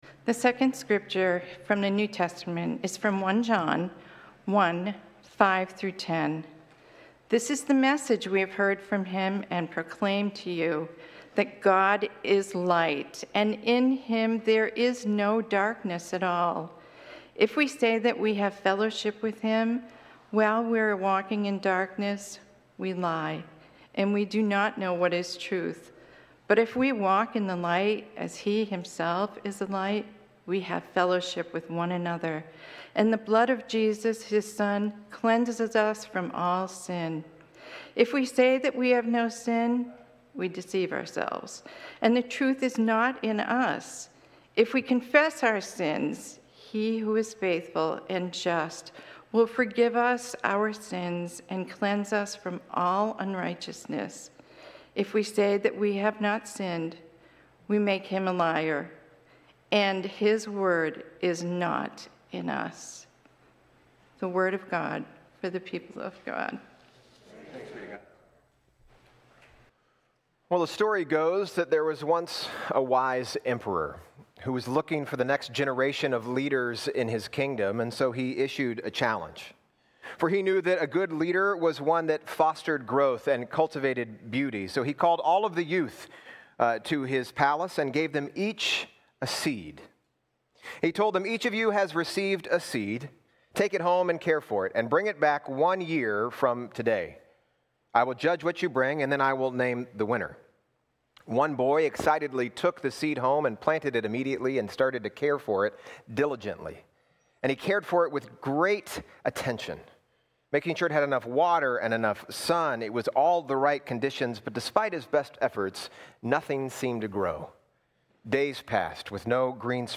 Sermon-3_10_25.mp3